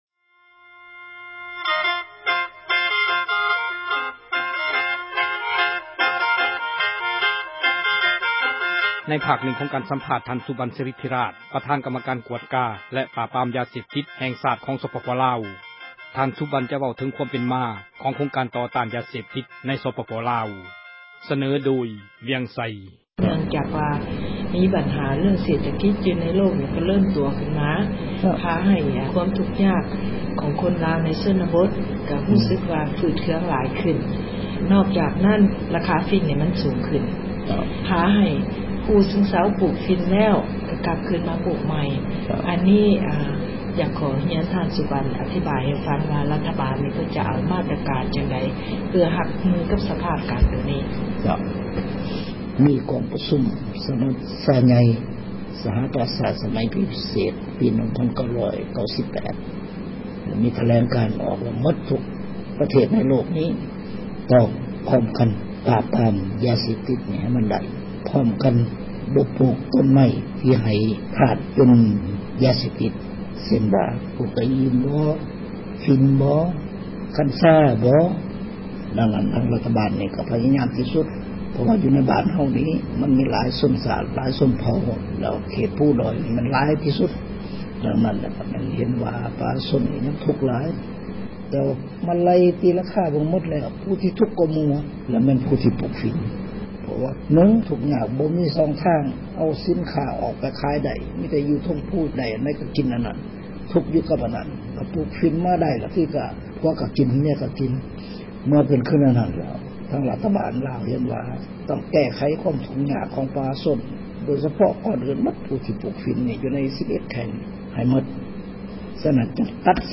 ສັມພາດ ທ່ານສຸບັນ ສາຣິດທິຣາຊ